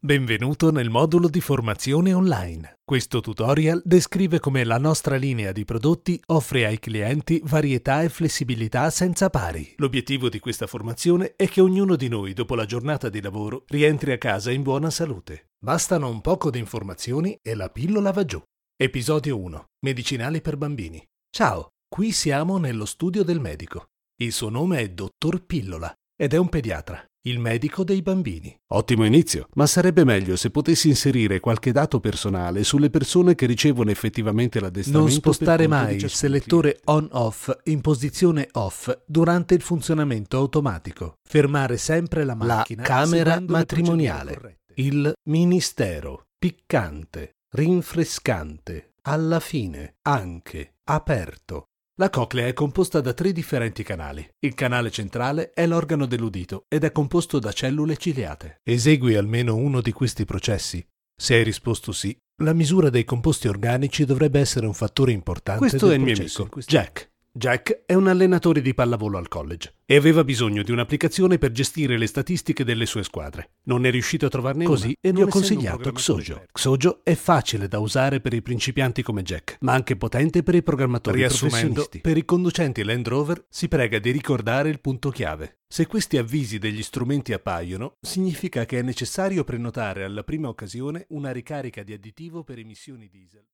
Commercial, Distinctive, Playful, Versatile, Mature
E-learning